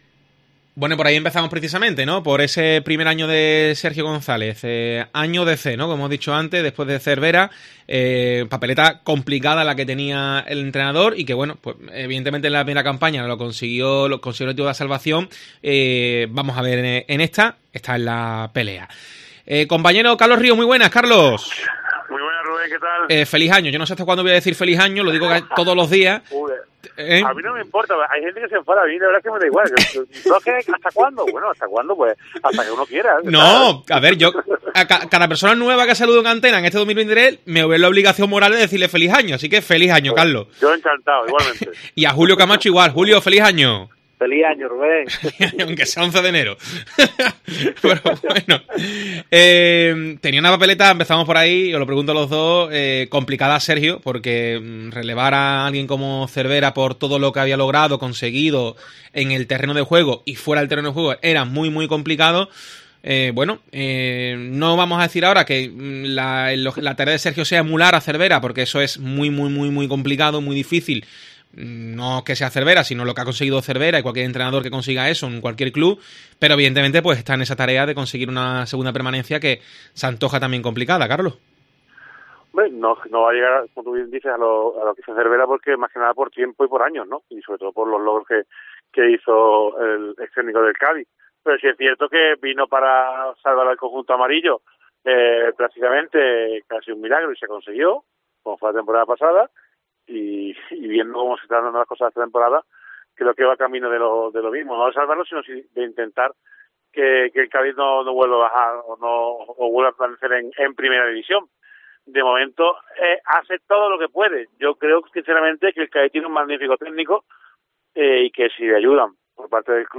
El debate de Deportes COPE sobre el Cádiz CF